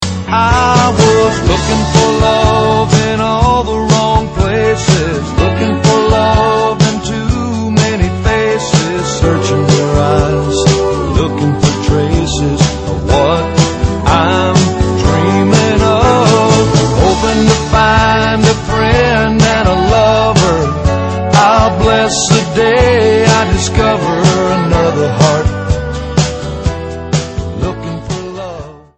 Tags: ringtones tones cell phone music melody country songs